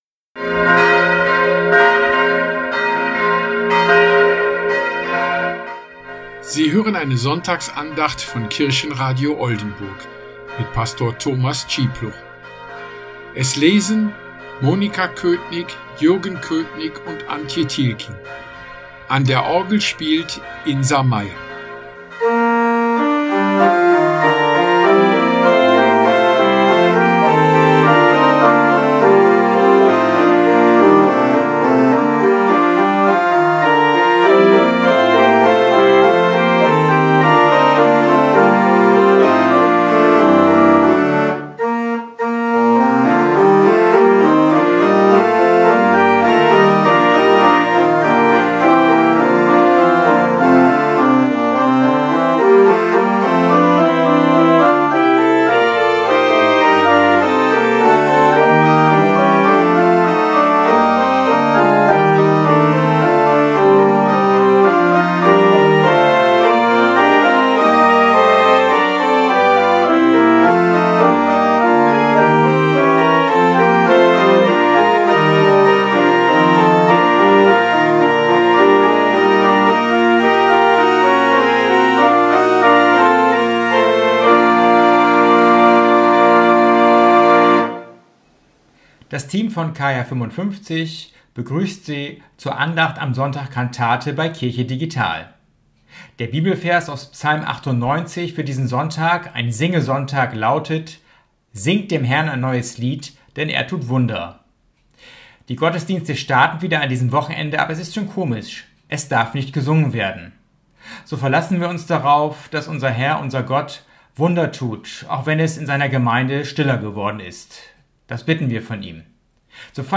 10. Mai 2020: Abschlussandacht KR55 – Kirchenradio Oldenburg
Diese Andacht wird am Sonntag, 10.5.2020 um 10:30 Uhrim Hörfunk von Oldenburg Eins gesendet.
Lied EG 302: Du meine Seele, singe